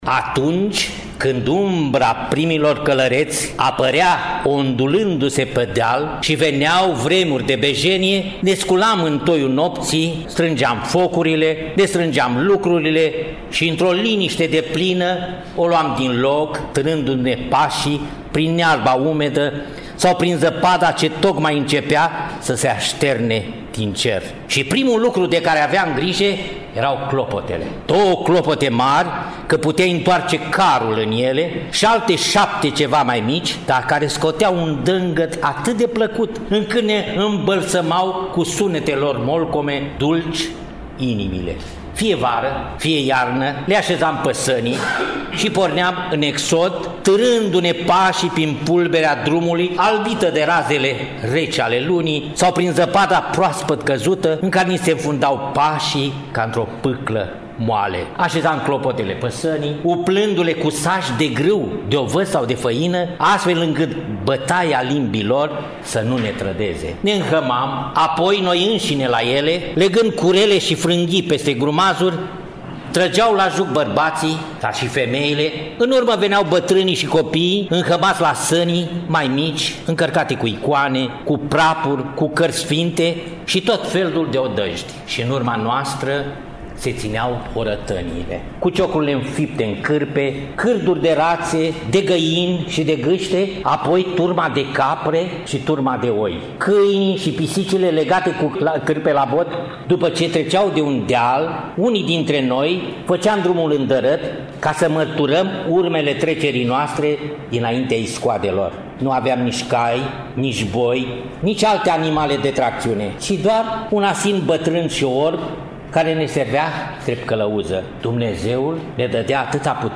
a recitat poezia Exodul, aparținând scriitorului Nichita Danilov, poezie pe care vă invităm să o audiați.